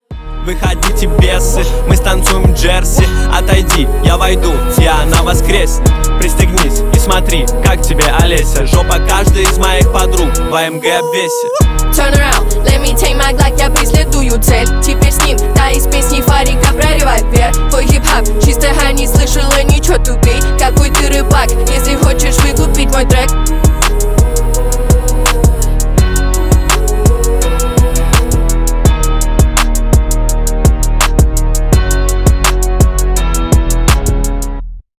Поп
Рэп рингтоны